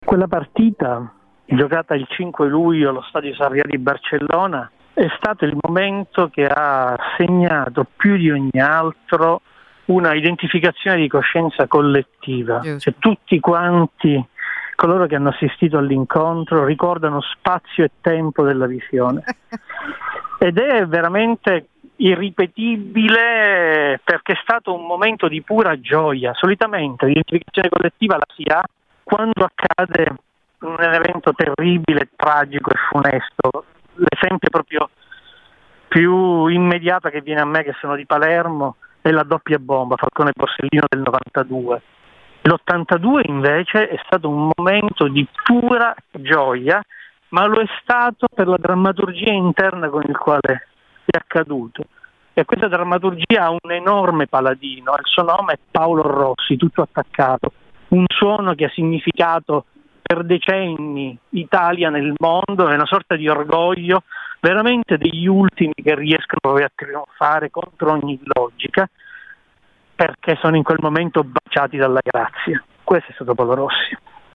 Il drammaturgo Davide Enia ne ha fatto una rappresentazione teatrale: Italia-Brasile 3 a 2.